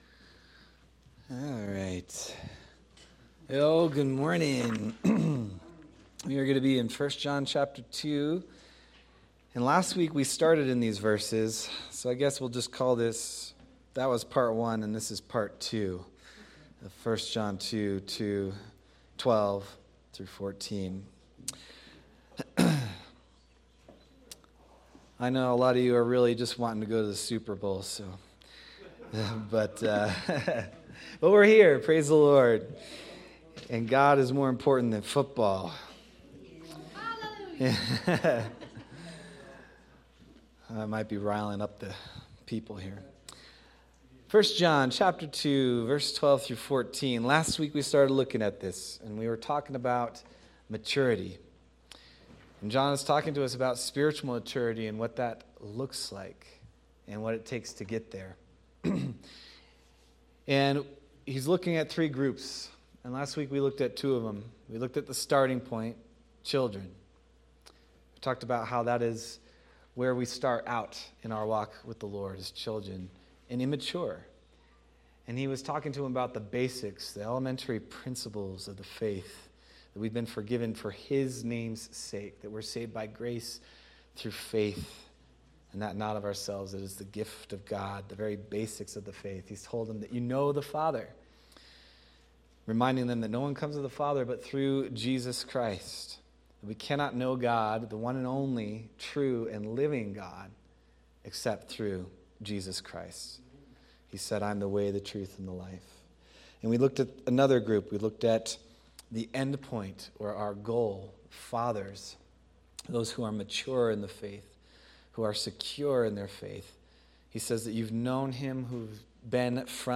February 8th, 2026 Sermon – Calvary Chapel Nederland